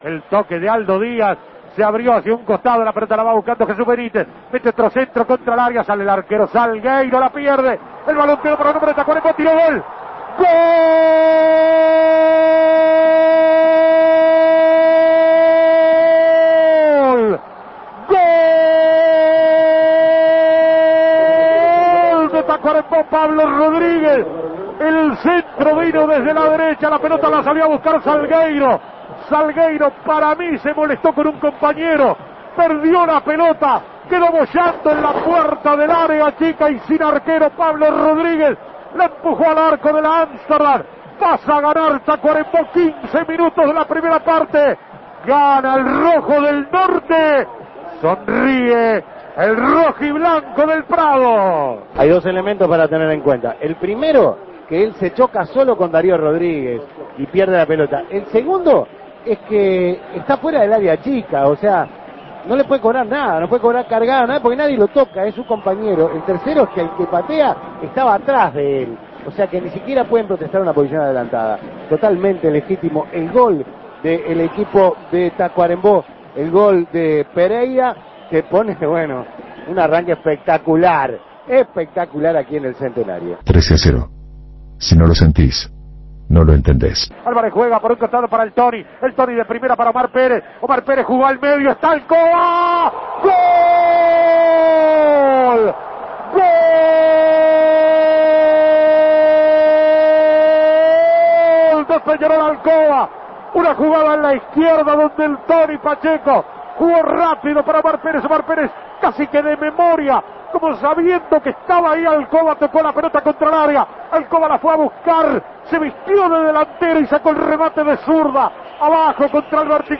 Goles y comentarios